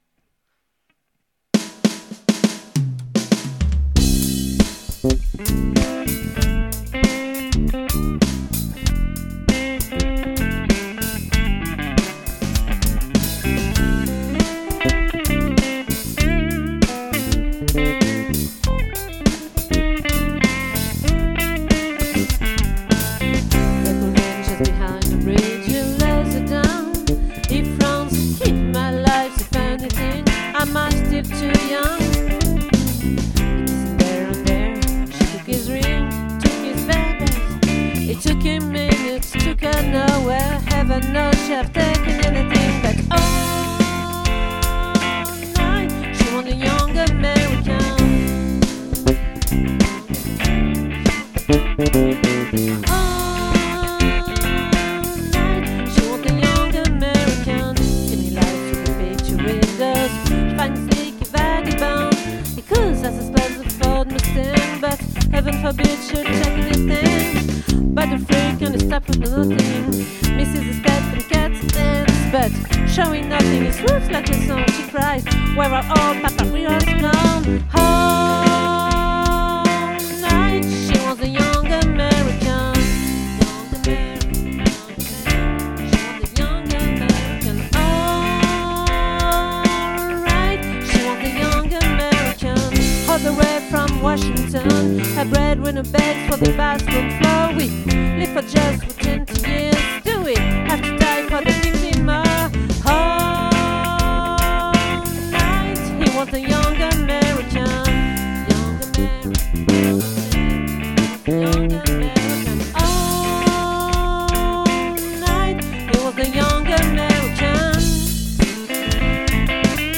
🏠 Accueil Repetitions Records_2022_01_19